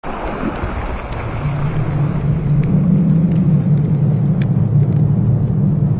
wind howling 5 .ogg
Original creative-commons licensed sounds for DJ's and music producers, recorded with high quality studio microphones.
Channels Stereo
[wind-howling]_(5)_pfb.mp3